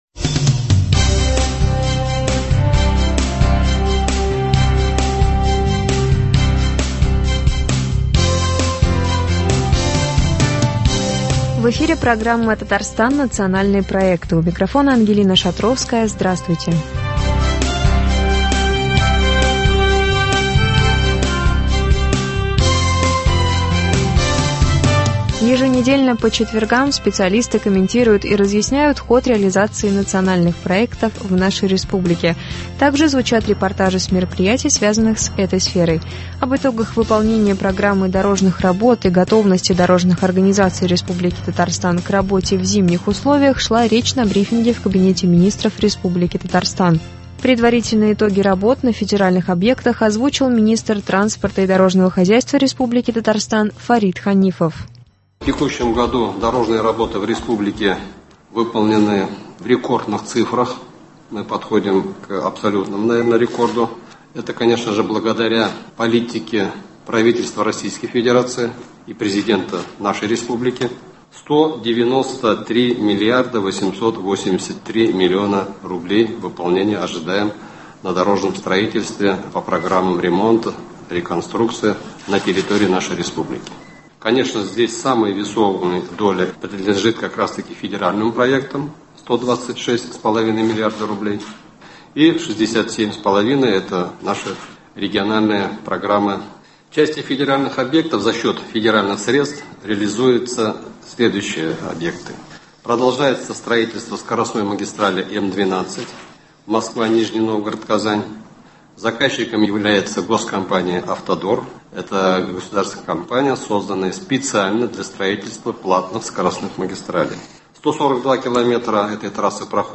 Об итогах выполнения Программы дорожных работ и готовности дорожных организаций Республики Татарстан к работе в зимних условиях шла речь на брифинге в Кабинете Министров РТ.